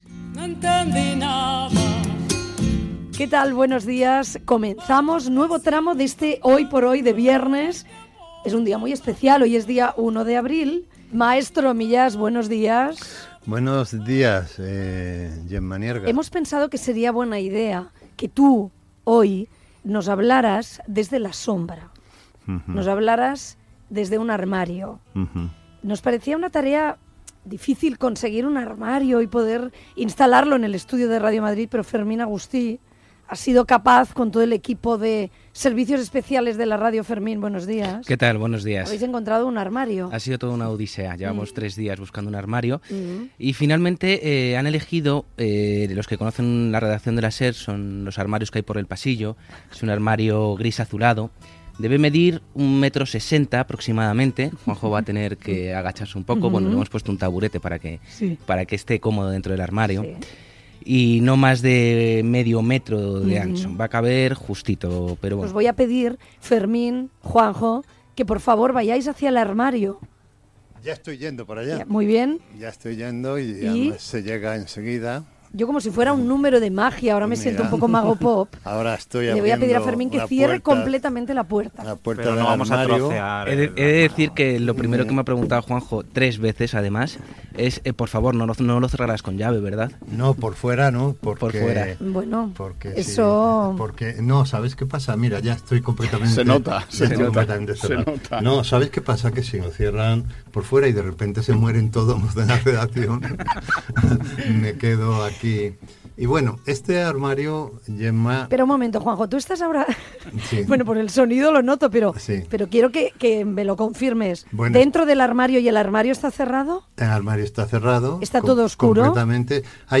Data, secció de Juan José Millás feta des de dins d'un armari que està al locutori de Radio Madrid
Info-entreteniment